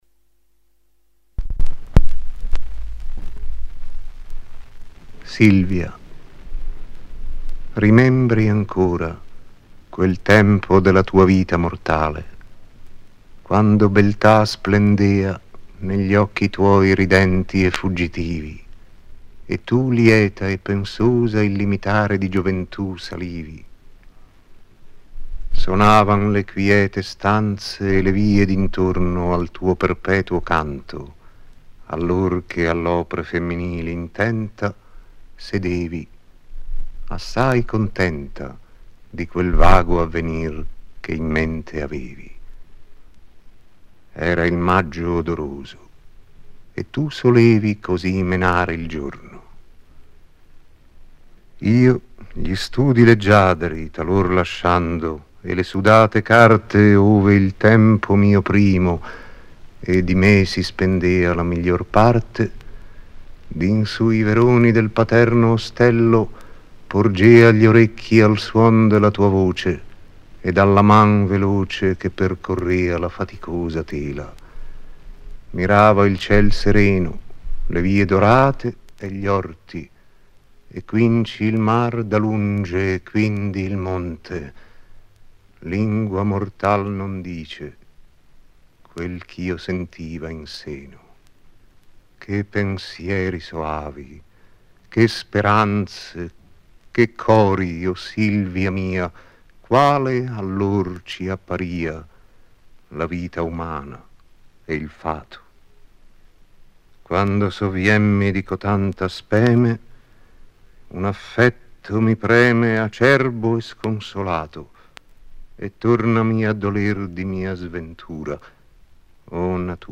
Vittorio Gassman recita: